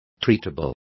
Also find out how tratables is pronounced correctly.